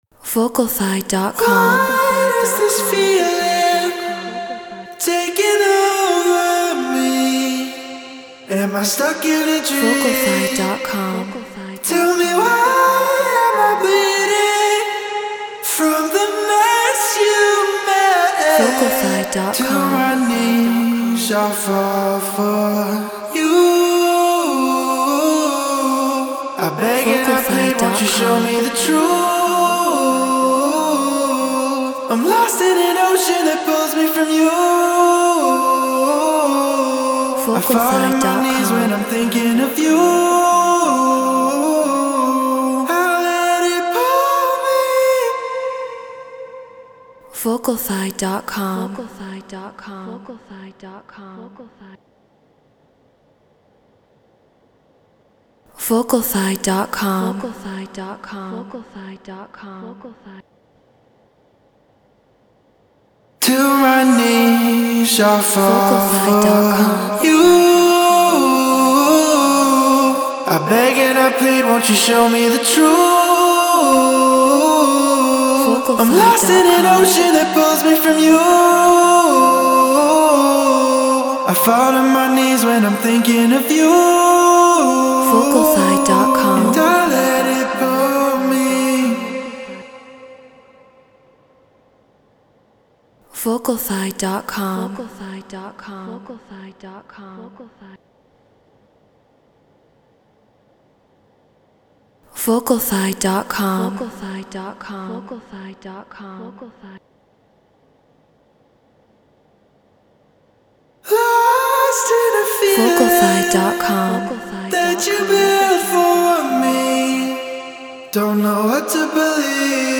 Drum & Bass 175 BPM Fmin
RØDE NT1 Focusrite Scarlett Solo FL Studio Treated Room